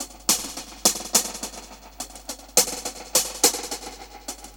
Index of /musicradar/dub-drums-samples/105bpm
Db_DrumsB_EchoHats_105-02.wav